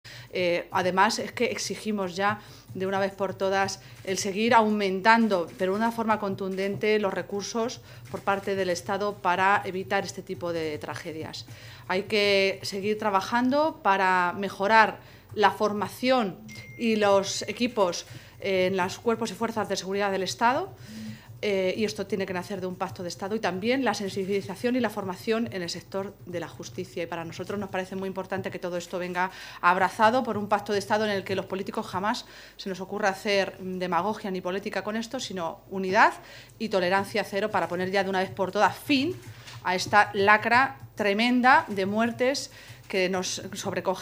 La vicesecretaria y portavoz del PSOE de Castilla-La Mancha, Cristina Maestre, ha condenado, en nombre los socialistas de Castilla-La Mancha, el brutal asesinato machista que se ha producido esta mañana en Daimiel, donde un hombre ha matado a su mujer y a su hija y trasladó nuestro más sentido pésame y apoyo a los familiares y amigos de la víctima.
Cortes de audio de la rueda de prensa